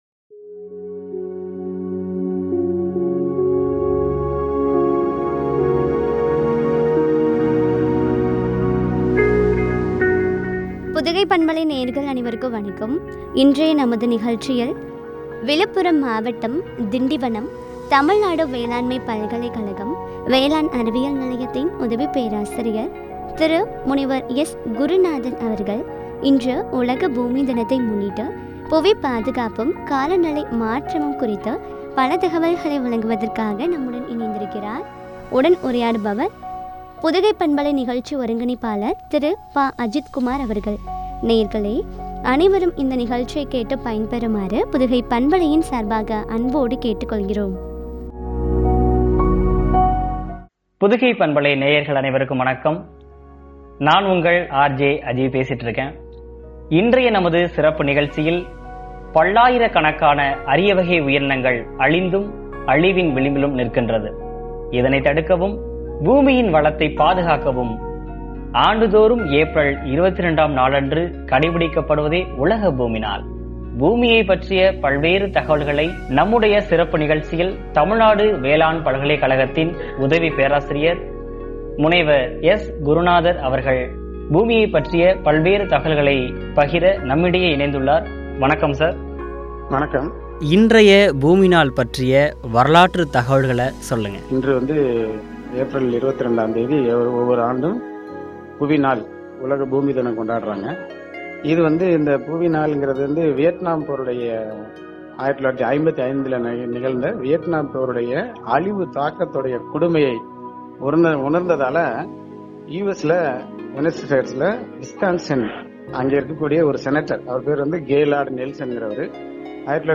காலநிலை மாற்றமும் என்ற தலைப்பில் வழங்கிய உரையாடல்.